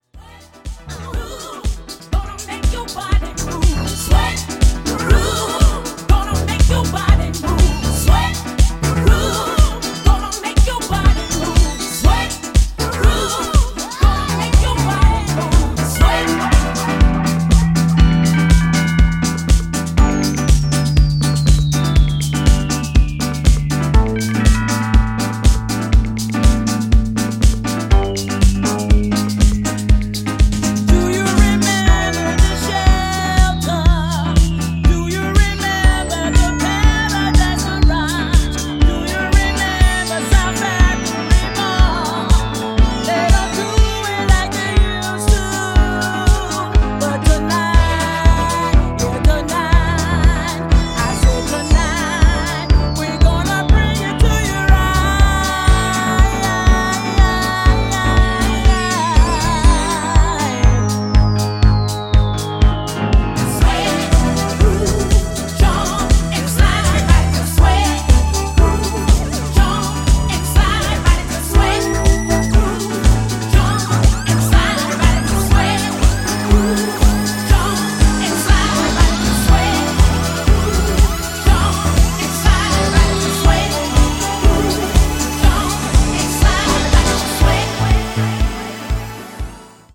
DISCO MIX
ジャンル(スタイル) HOUSE / SOULFUL HOUSE / DISCO HOUSE